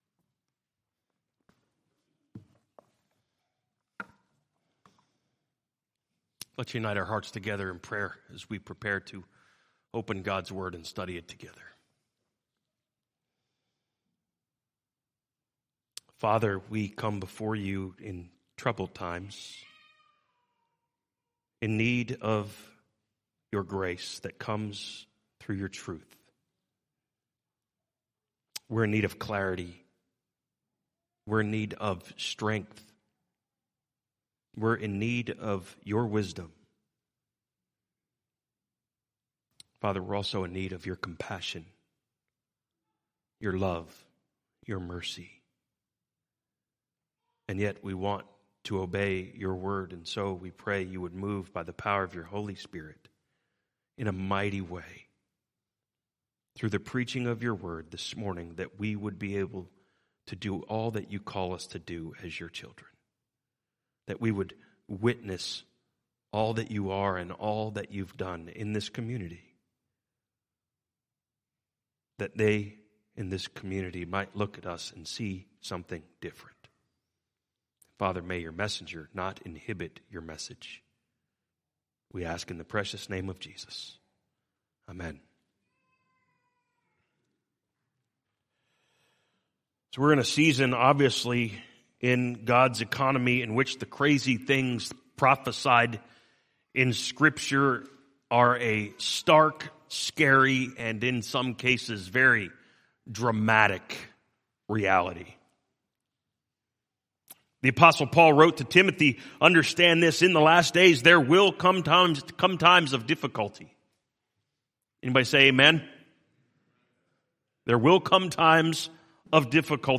A message from the series "2023 Sermons."